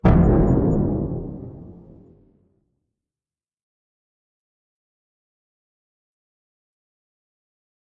贡品大炮
描述：我想为我正在开发的游戏制作声音效果，所以我在威廉姆斯营（犹他州国民警卫队）录制了一门100毫米火炮，然后用Audacity修改了声音。 录音是在2000年代中期（第一个十年）在奥林巴斯数字录音机上完成的。
标签： 战争 军事 饥饿游戏 火炮 军队 爆炸
声道立体声